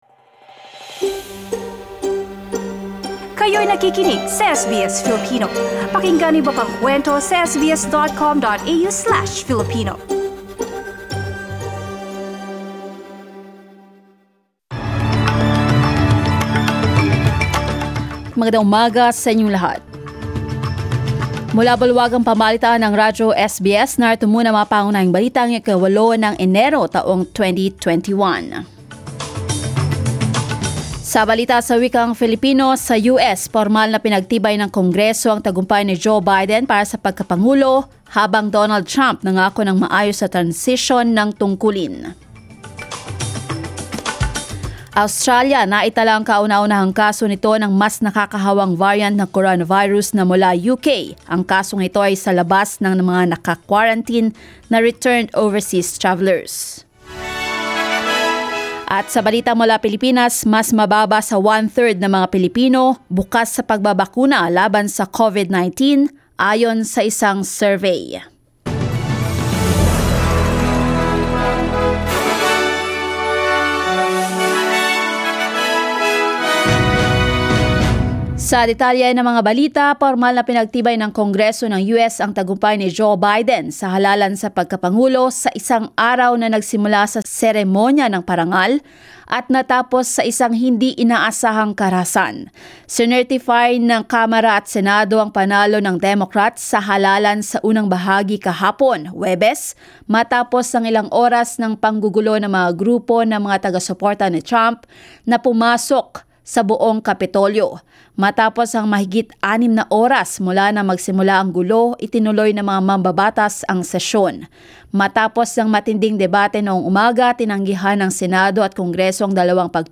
SBS News in Filipino, Friday 08 January